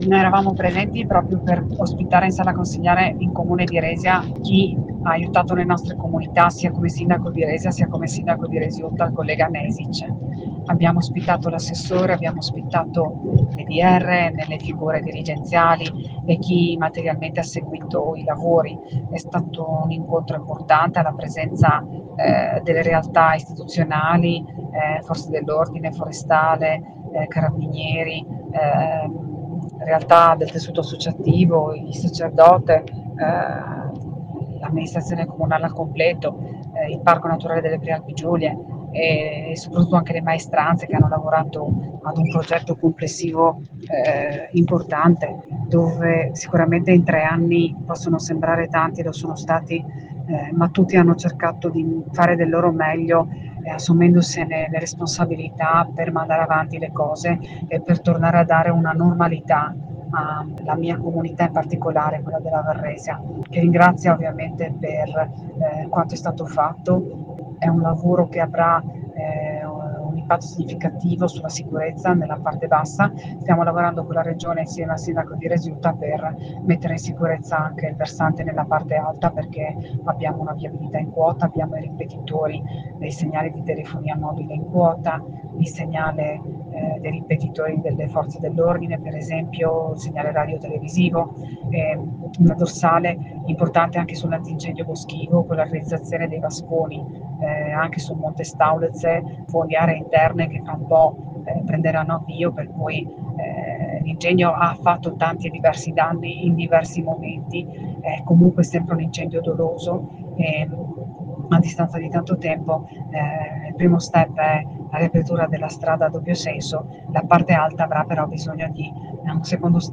Proponiamo l’audio con le parole del sindaco di Resia Anna Micelli e le dichiarazioni video dell’assessore Amirante.